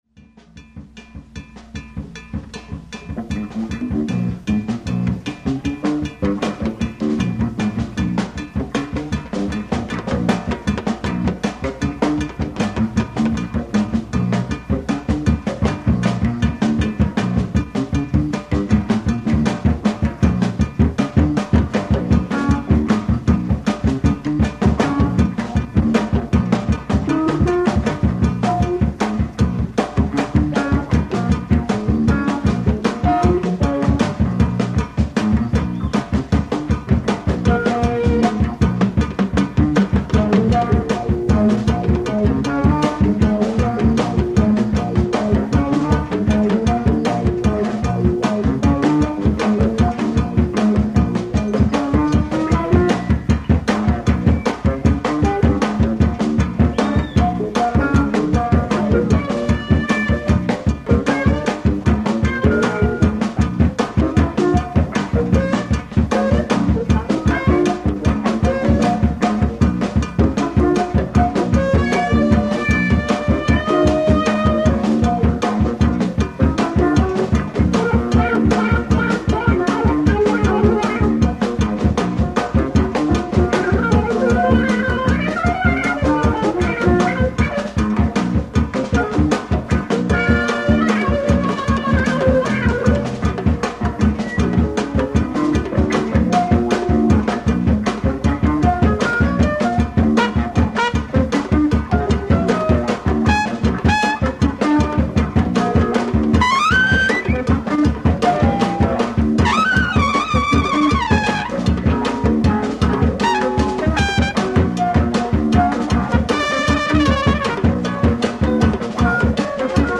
ライブ・アット・フィルモア・ウエスト、サンフランシスコ 10/15/1970
※試聴用に実際より音質を落としています。